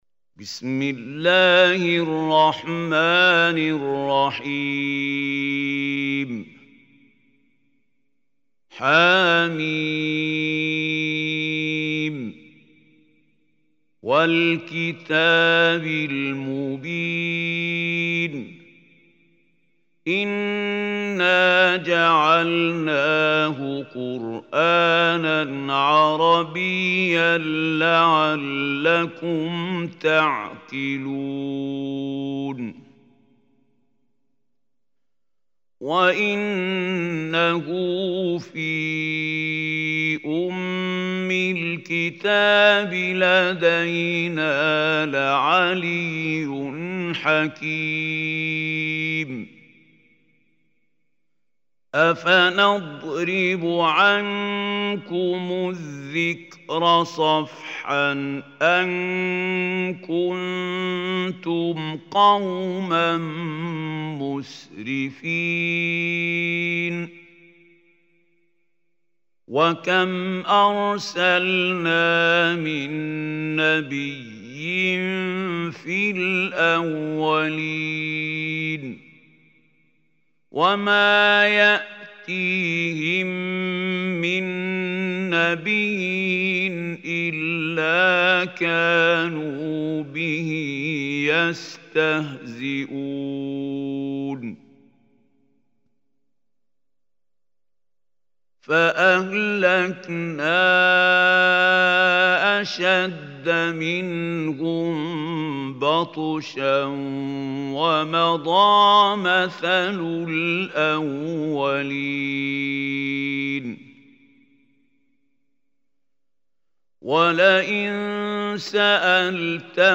Surah Zukhruf Recitation by Mahmoud Khalil Hussary
Surah Zukhruf is 43 surah of Holy Quran. Listen or play online mp3 tilawat/ recitation in the beautiful voice of Mahmoud Khalil Hussary.